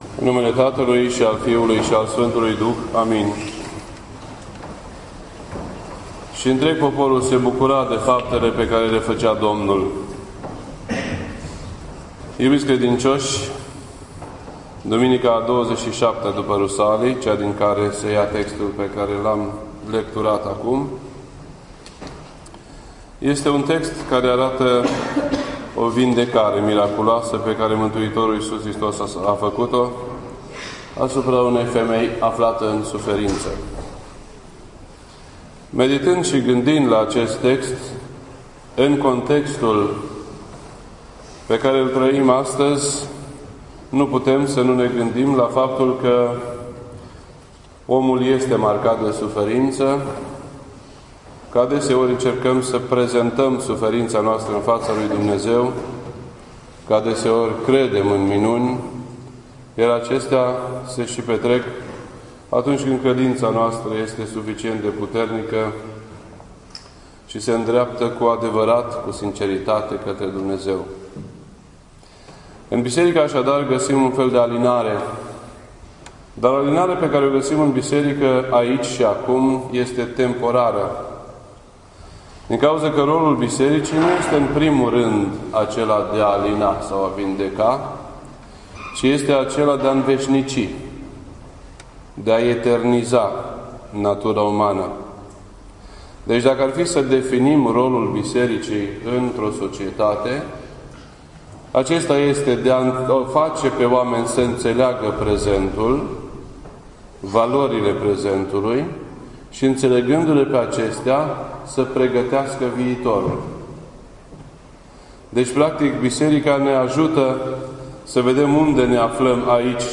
This entry was posted on Sunday, December 7th, 2014 at 9:06 PM and is filed under Predici ortodoxe in format audio.